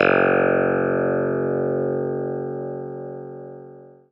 CLAVI1.01.wav